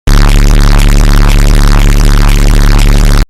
Bass Boost sound effect.mp3